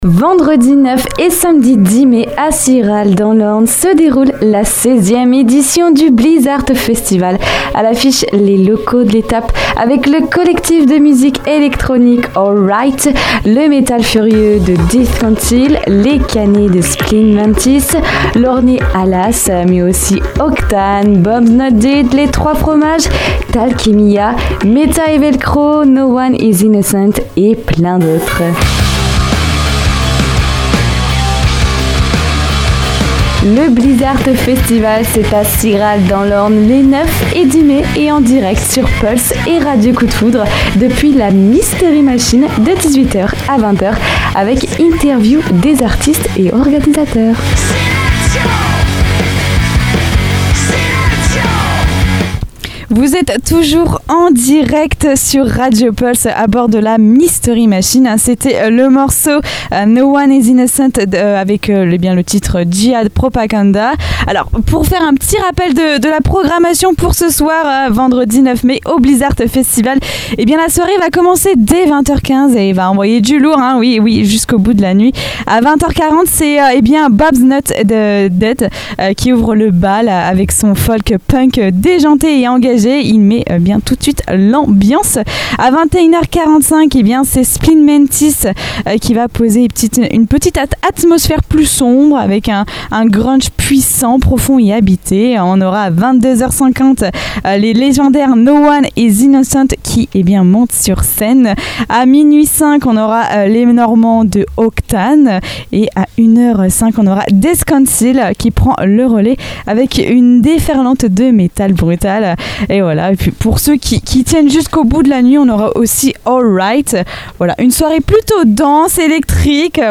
À cette occasion, nous avons eu le plaisir d'interviewer Death Council en direct depuis la Mystery Machine, le studio mobile de Radio Pulse, avec une diffusion simultanée sur Pulse et Radio Coup de Foudre.